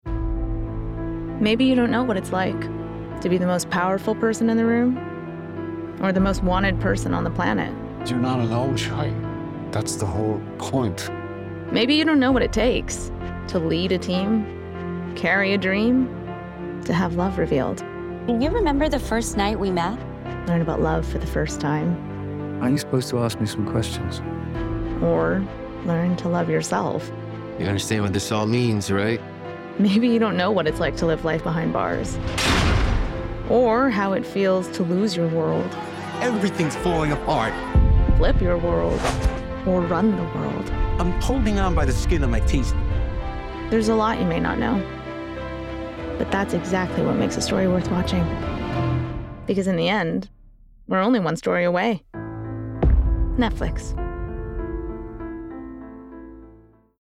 STYLE: Conversational
20/30's American-Chinese, Versatile/Contemporary/Youthful